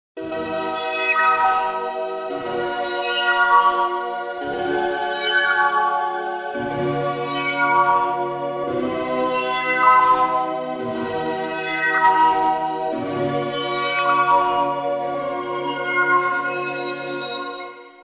Pad: too much TVF
pad-lotresonance.mp3